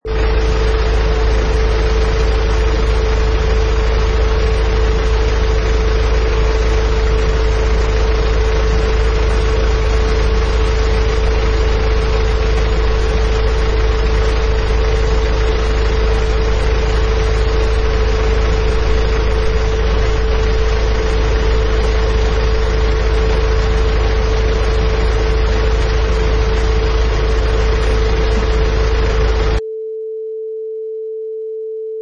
Sound effects: Truck Idling
Large truck idling
Product Info: 48k 24bit Stereo
Category: Vehicles / Trucks - Idling
Try preview above (pink tone added for copyright).
Truck_Idling.mp3